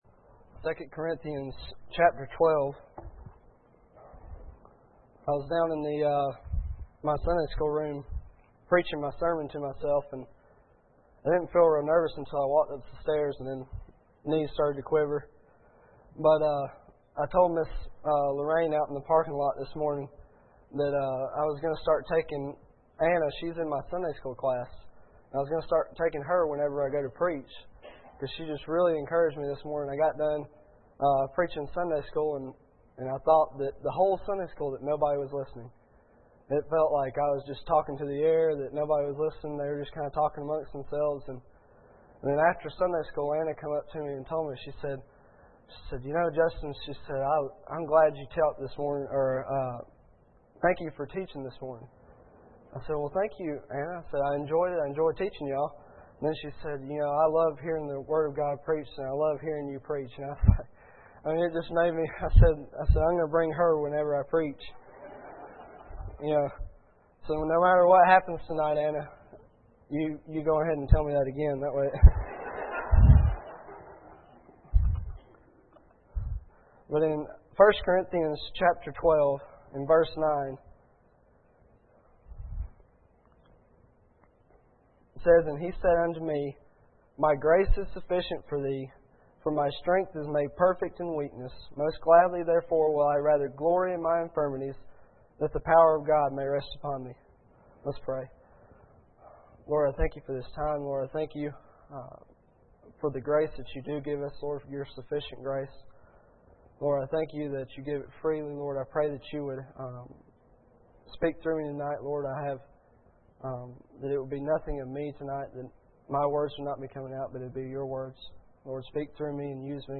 2 Corinthians 12:9 Service Type: Sunday Evening Bible Text